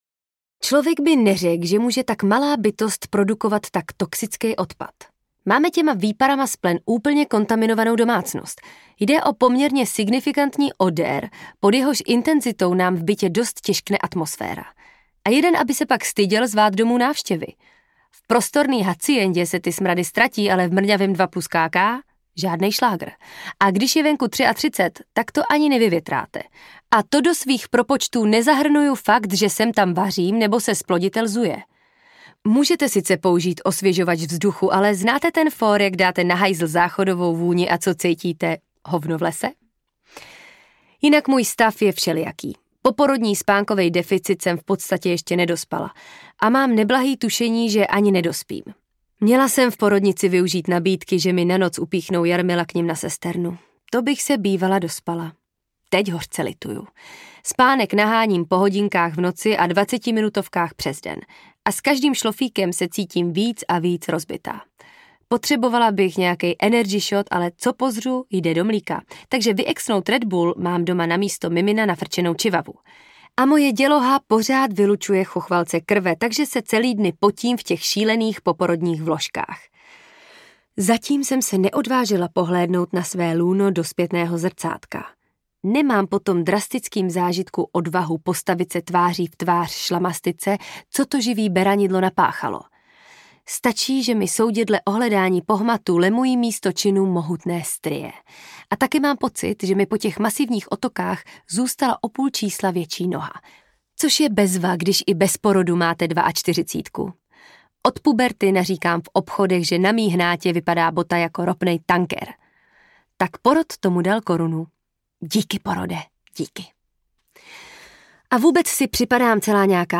Ukázka z knihy
Vyrobilo studio Soundguru.